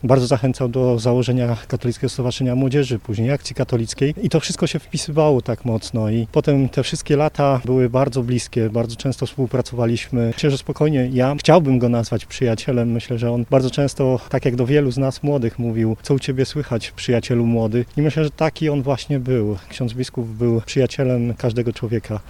– Biskup Adam Dyczkowski był przyjacielem dla wszystkich – dodaje Piotr Barczak, przewodniczący Rady Miasta w Zielonej Górze: